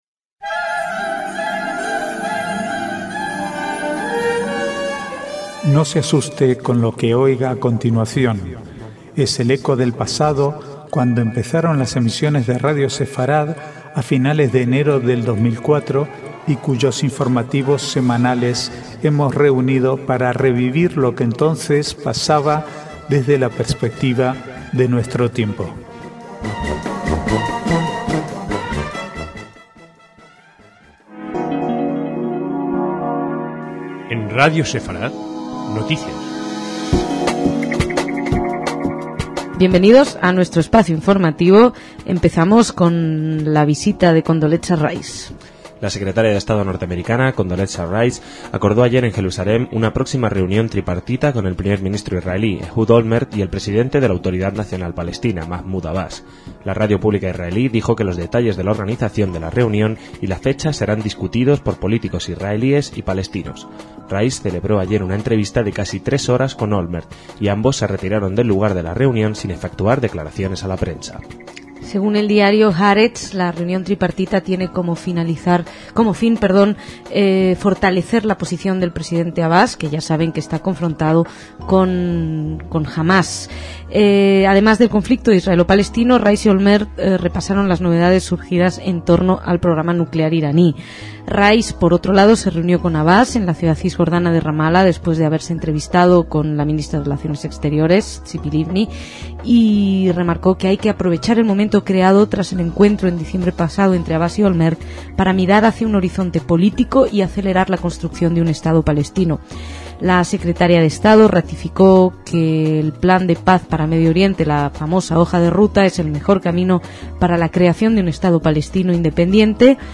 Archivo de noticias del 16 al 18/1/2007